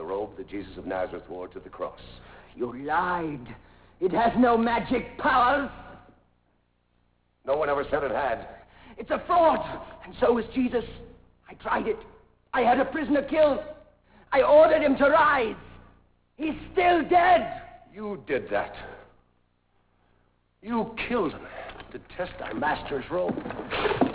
Hear audio of Victor from "Demetrius And The Gladiators" and view handsome Victor in some scenes from this classic movie.